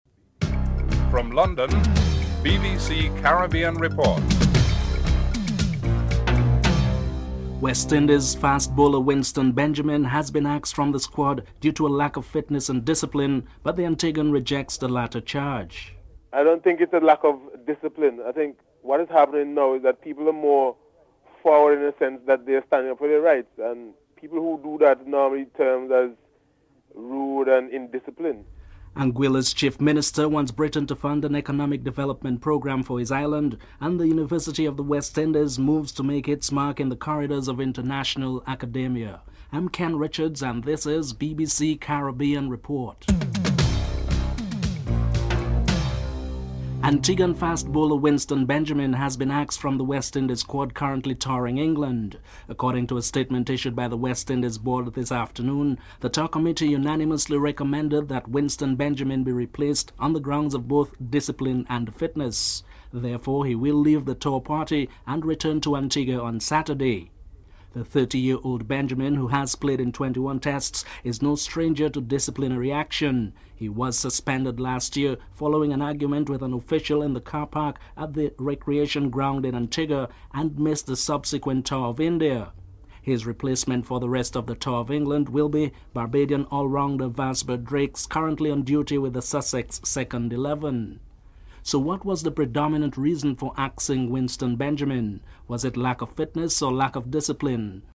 Former West Indies captain Sir Gary Sobers talks about the state of West Indies cricket.